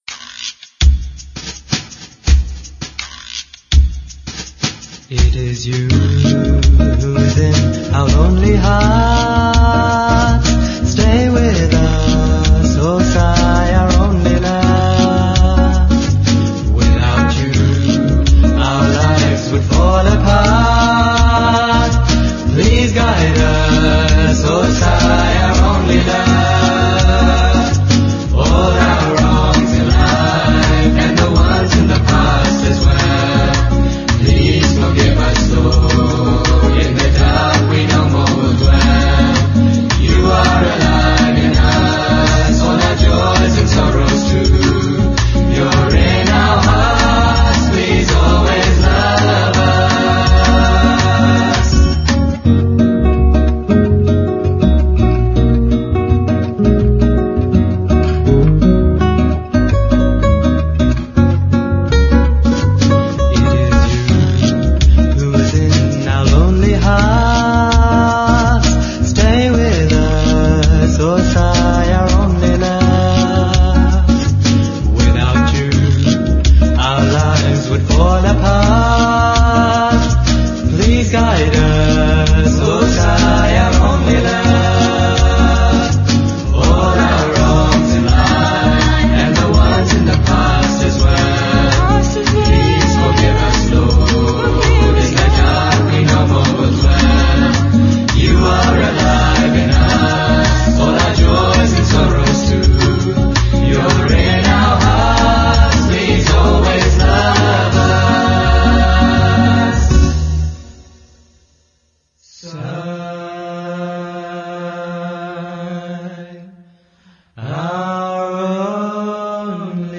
1. Devotional Songs
8 Beat / Keherwa / Adi
Medium Fast